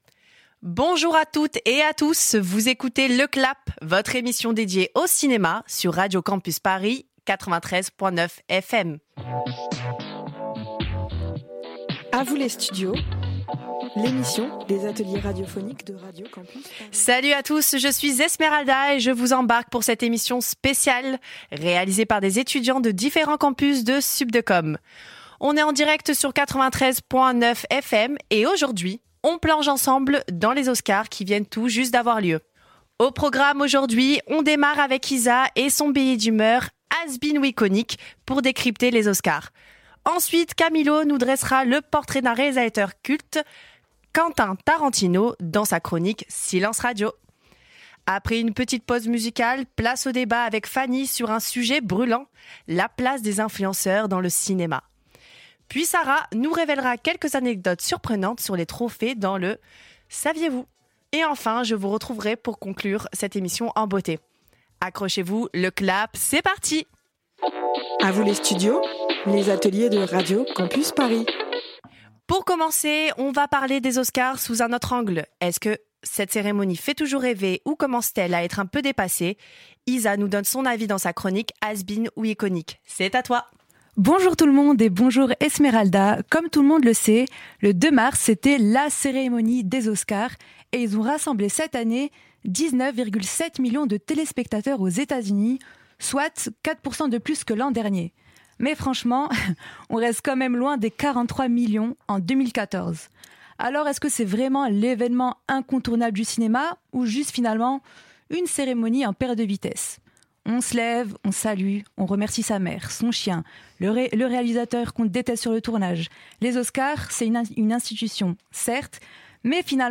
À l'occasion de la semaine de découverte de la radio, les étudiants et étudiantes en M2 Communication de différents campus de l'école SUP'DE COM ont écrit et réalisé deux émissions :